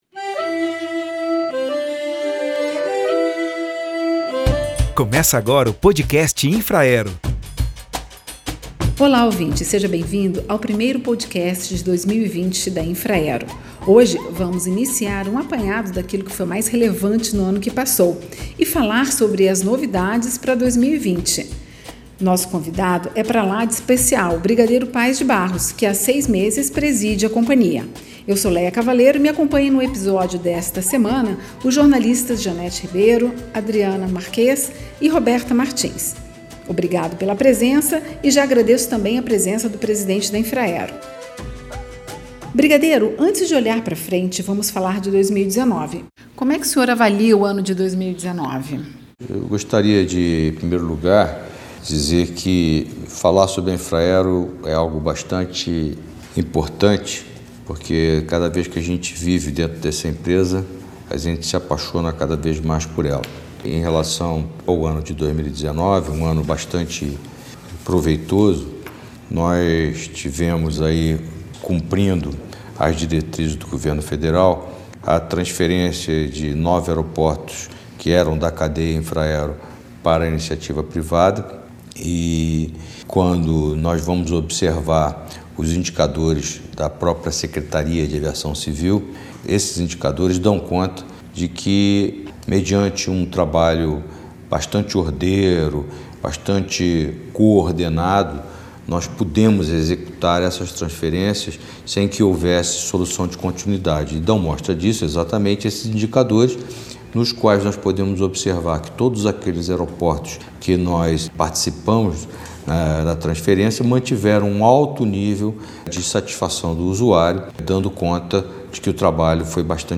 O Podcast Infraero traz uma entrevista com o presidente da Infraero, Brigadeiro Paes de Barros. Neste episódio, ele aborda a atuação da Infraero ao longo de 2019 e fala das perspectivas para este ano.